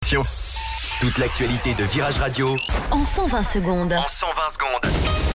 Flash Info